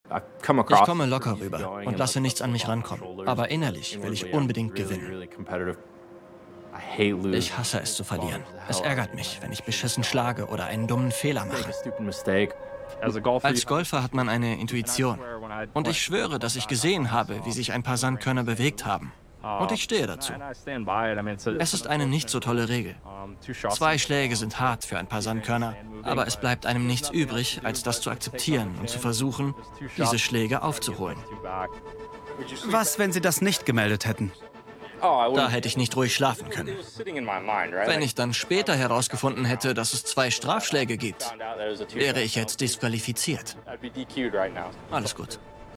hell, fein, zart, markant, sehr variabel
Jung (18-30)
Audio Drama (Hörspiel)